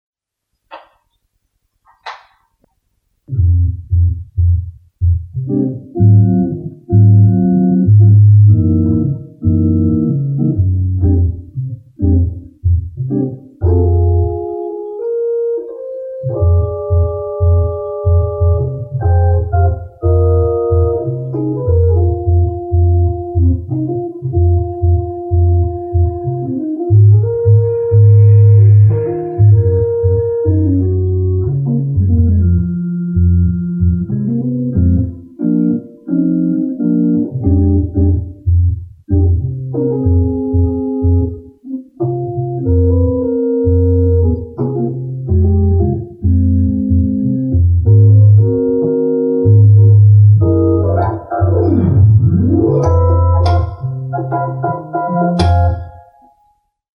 Je me suis donc finalement décidé à enregistrer le X-5.
Donc 2 petites impros prises sur le vif - soyez indulgents.
La 1ère, le micro (petit machin) est posé sur la Leslie, je sais, c'est pas malin et la 2ème, sur le côté.
Sur le premier, le son est un peu plus "rond" que celui d'un "roues phoniques" et fait penser au bourdon d'un orgue à tuyaux.
J'aime beaucoup ton 1er morceau avec cette basse régulière.